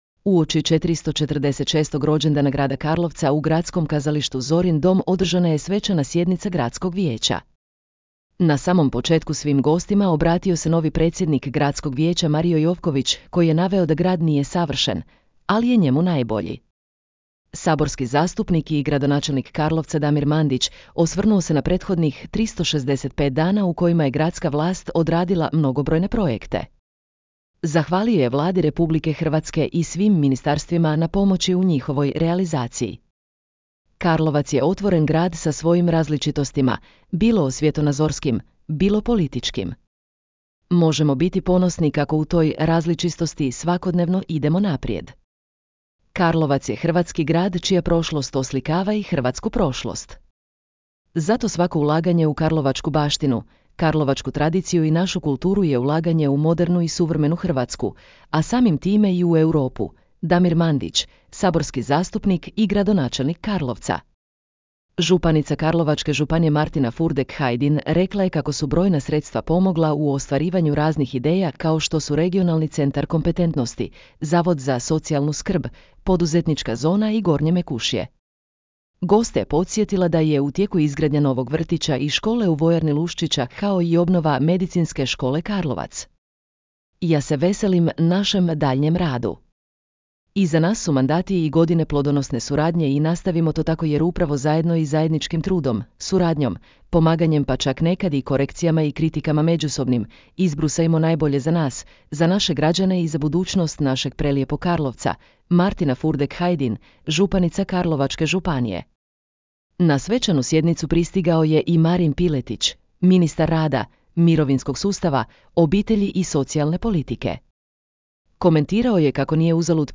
Uoči 446. rođendana Grada Karlovca u Gradskom kazalištu Zorin dom održana je svečana sjednica Gradskog vijeća. Na samom početku svim gostima obratio se novi predsjednik Gradskog vijeća Mario Jovković koji je naveo da grad nije savršen, ali je njemu najbolji. Saborski zastupnik i gradonačelnik Karlovca Damir Mandić osvrnuo se na prethodnih 365 dana u kojima je gradska vlast odradila mnogobrojne projekte.